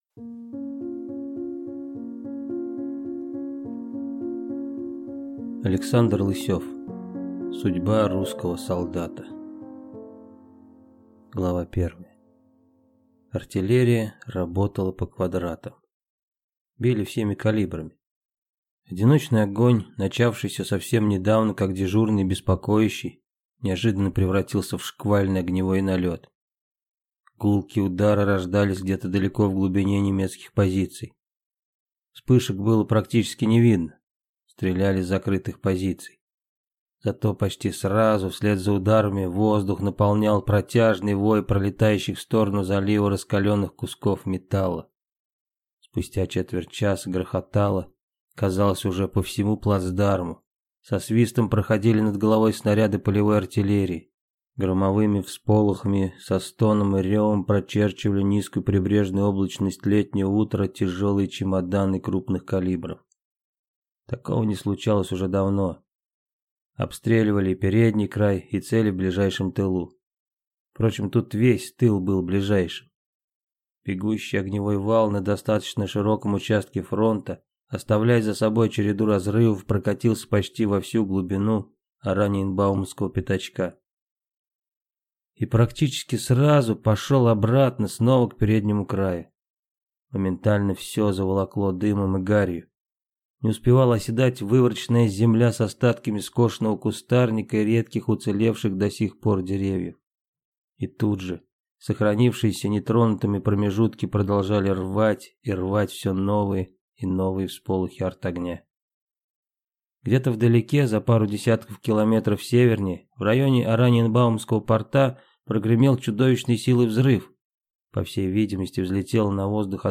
Аудиокнига Судьба русского солдата | Библиотека аудиокниг